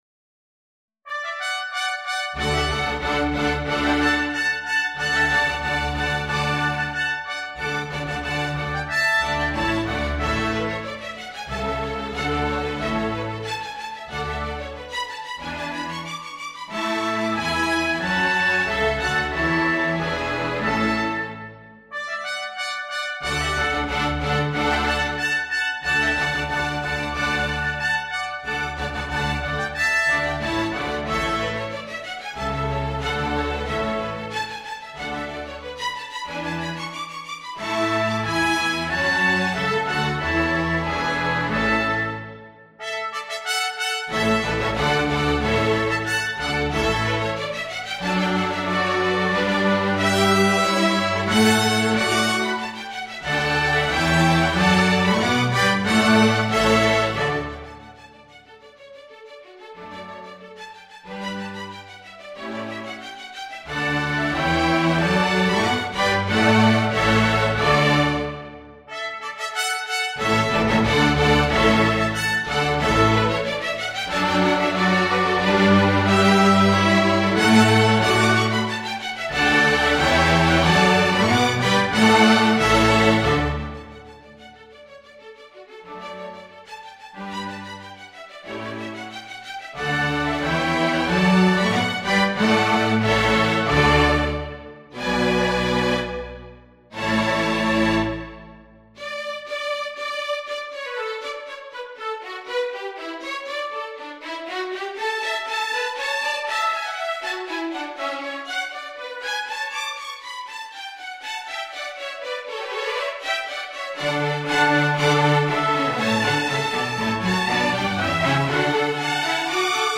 2 Oboes [optional], 2 Trumpets
Violin 1, Violin 2, Viola (or Violin 3), Cello, Bass